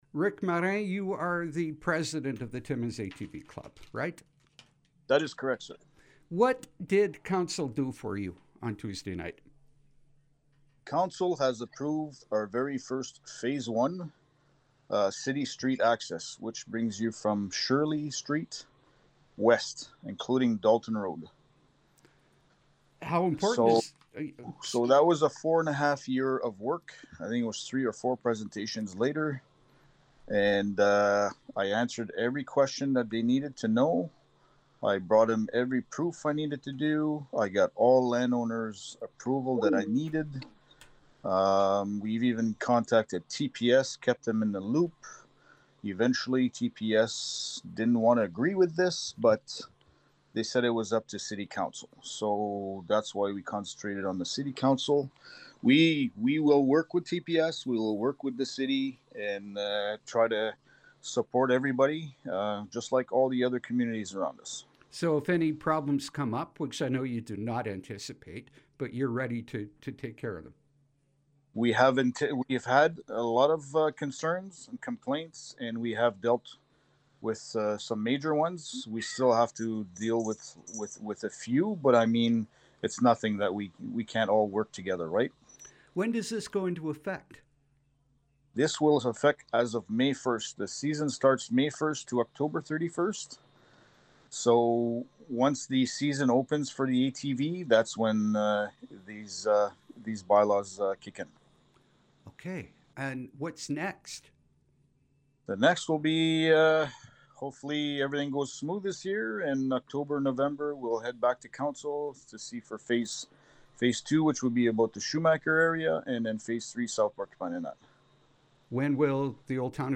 Listen to our interview for more information and how to get an ATV permit, which you need to use streets and trails.